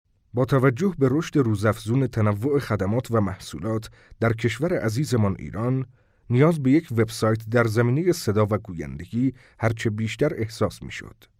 Male
Senior
Dacumentry